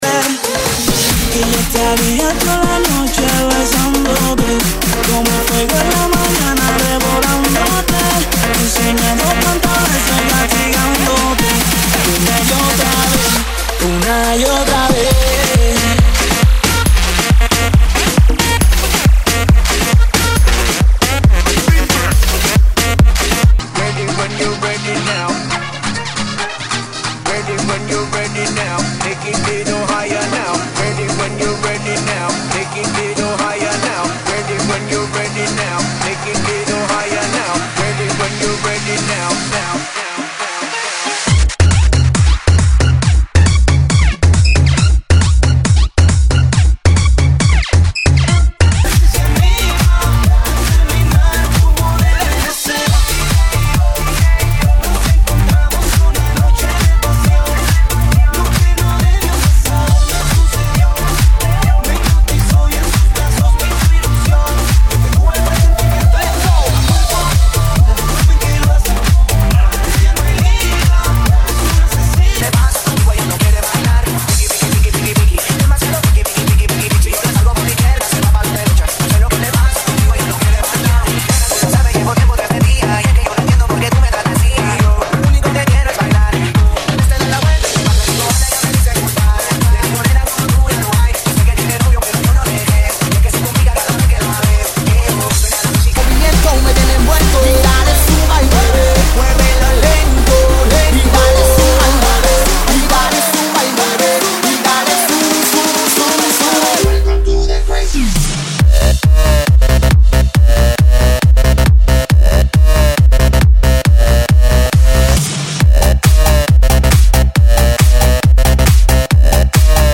GENERO: REGGAETON, LATINO, REMIX,